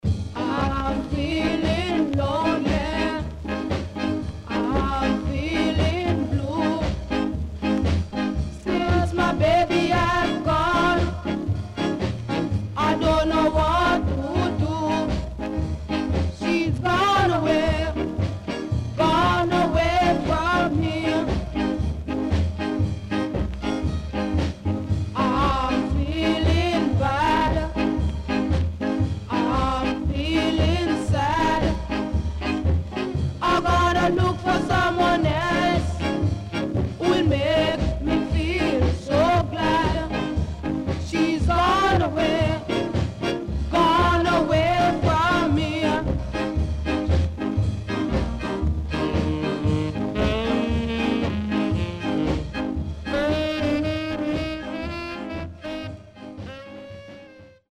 CONDITION SIDE A:VG(OK)〜VG+
SIDE A:所々チリノイズがあり、少しプチノイズ入ります。